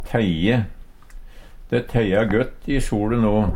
DIALEKTORD PÅ NORMERT NORSK teie tine Infinitiv Presens Preteritum Perfektum teie teia teia teia Eksempel på bruk De teia gøtt i sole no. Hør på dette ordet Ordklasse: Verb Kategori: Vêr og føre Attende til søk